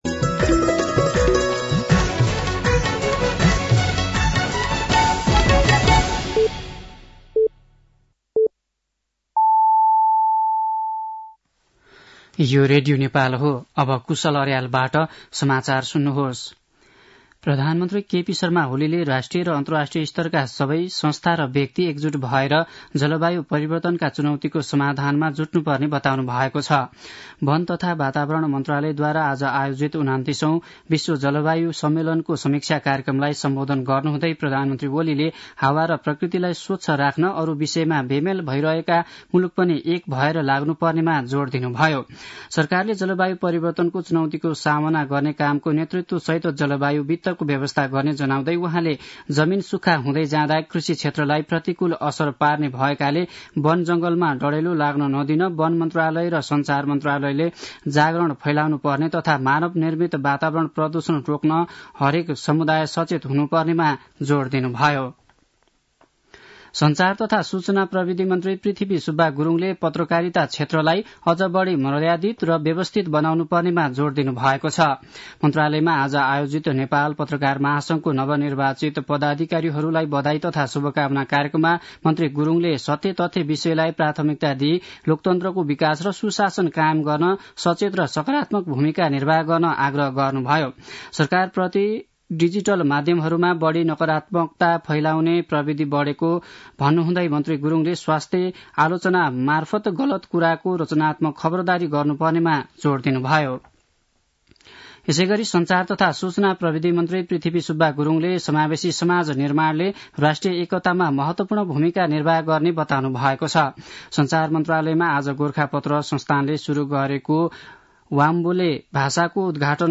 साँझ ५ बजेको नेपाली समाचार : १२ पुष , २०८१